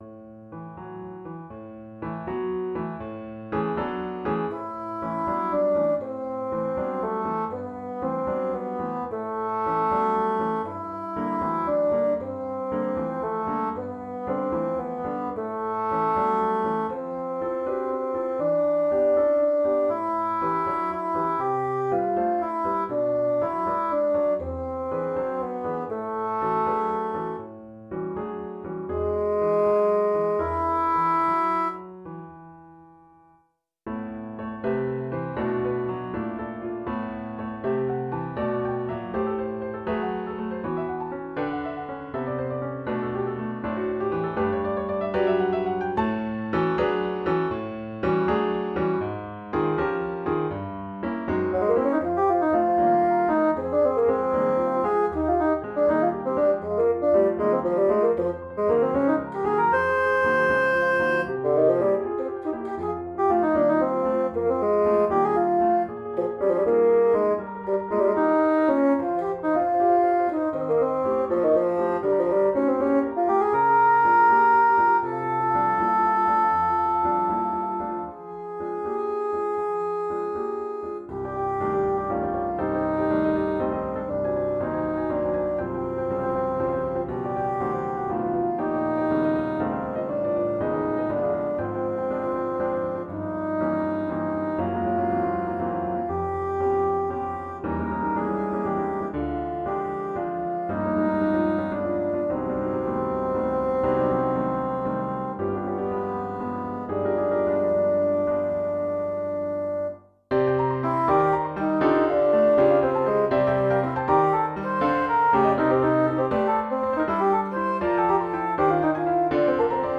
Bassoon